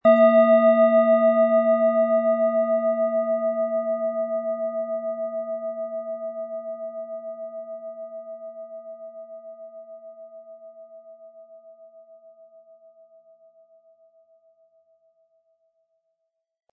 Planetenton 1
Die Schale mit Biorhythmus Seele, ist eine in uralter Tradition von Hand getriebene Planetenklangschale.
Wie klingt diese tibetische Klangschale mit dem Planetenton Biorhythmus Seele?
MaterialBronze